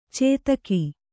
♪ cētaki